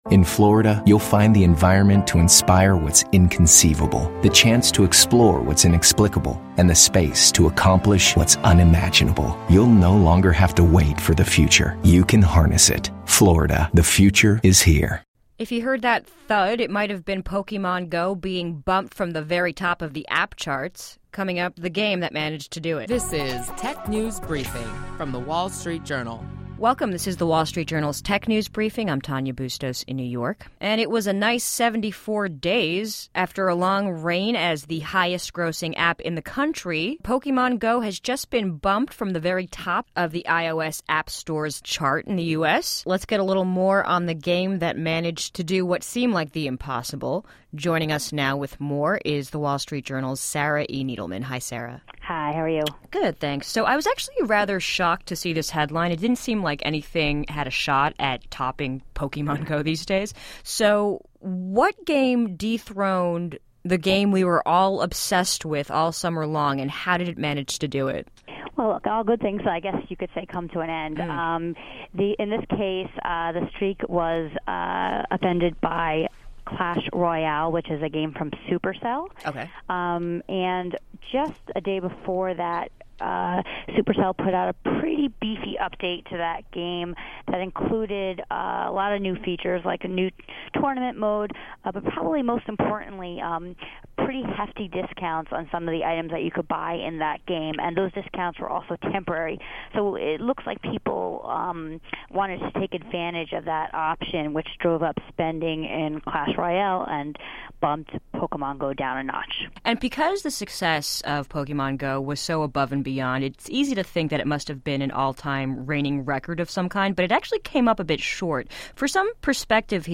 Stay informed on the latest technology trends with daily insights on what’s hot and happening in the world of technology. Listen to our WSJD reporters discuss notable tech company news, new tech gadgets, personal technology updates, app features, start-up highlights and more.